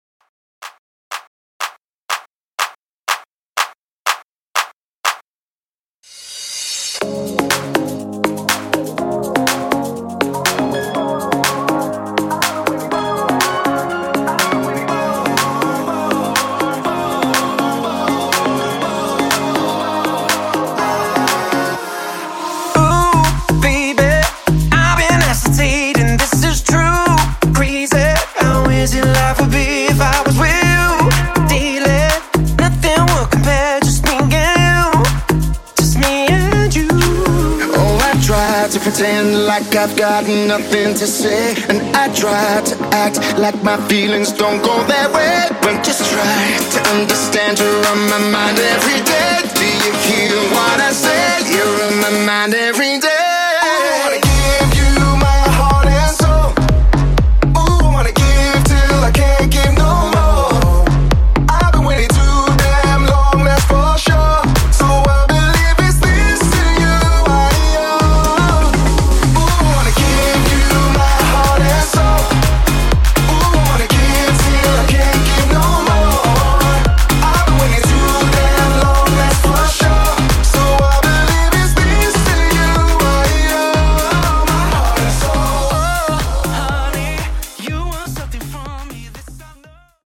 In-Outro Edit)Date Added